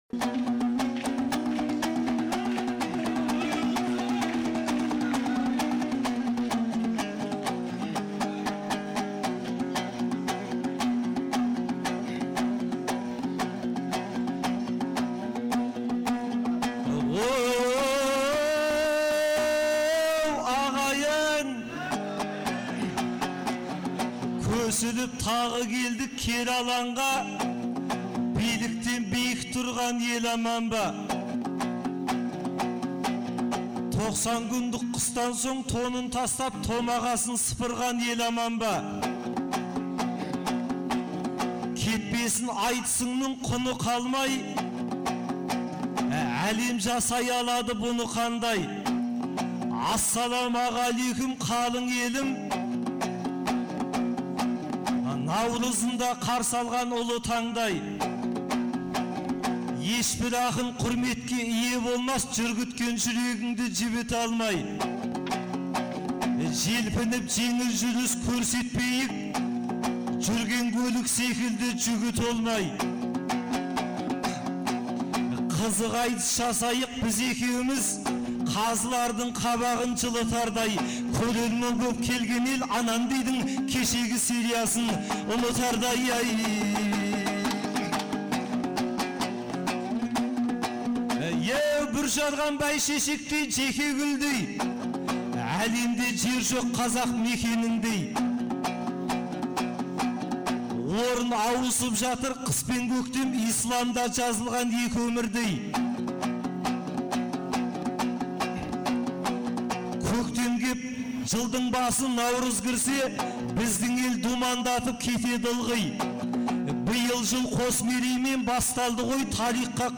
Наурыздың 15-16 күндері Шымкент қаласында екі күнге созылған республикалық «Наурыз» айтысы өткен болатын. 2004 жылдан бері тұрақты өтіп келе жатқан айтыс биылғы жылы Төле бидің 350 және Абылай ханның 300 жылдықтарына арналды.